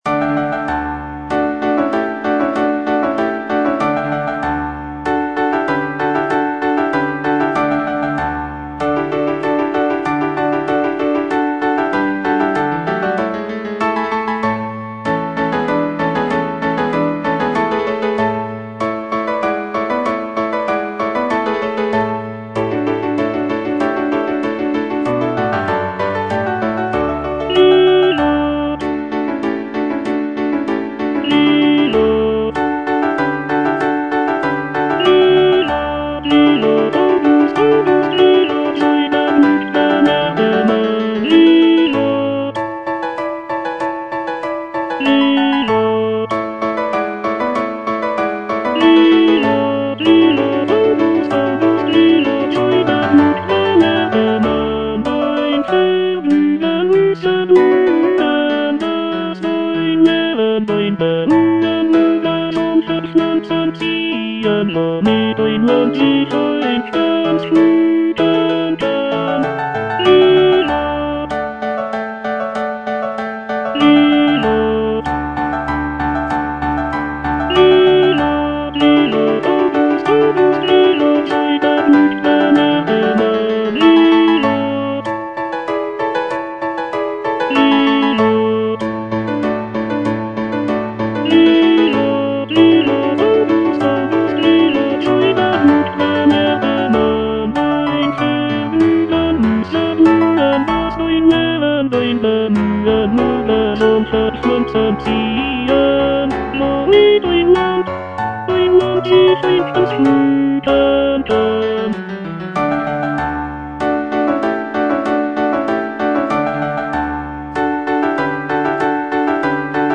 Choralplayer playing Cantata
The music is lively and celebratory, with intricate counterpoint and virtuosic vocal lines.